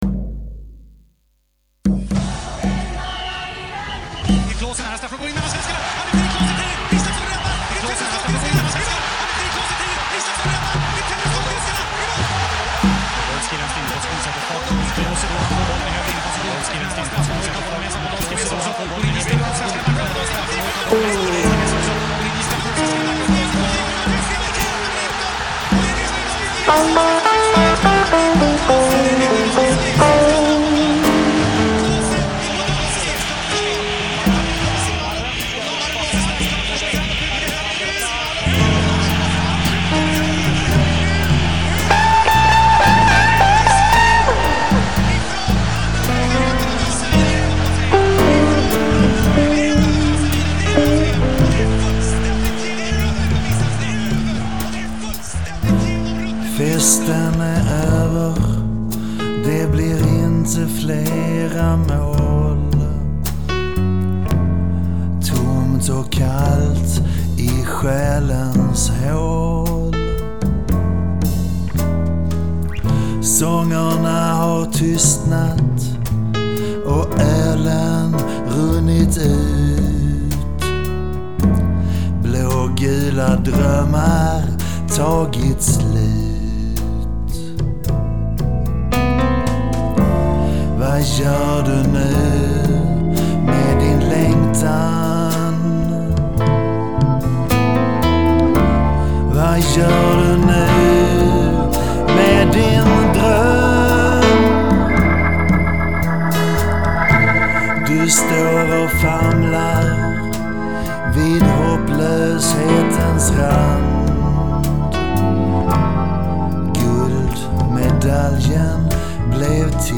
Gitarr
Orgel
Hammond